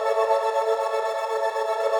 SaS_MovingPad05_120-A.wav